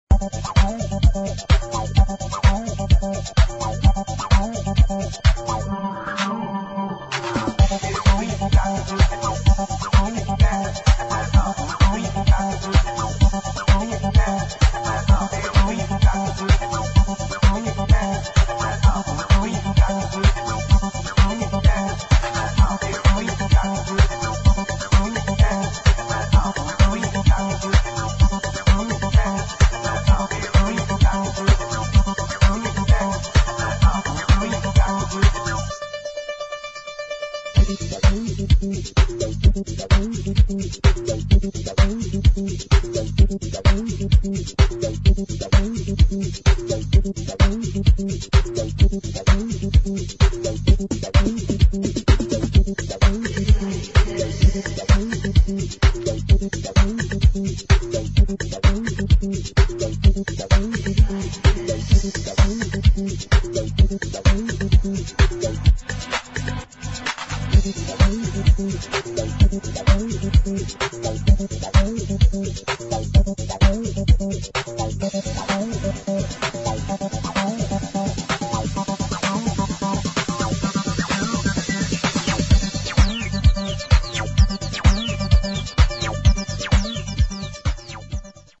[ HOUSE / ELECTRO / ACID ]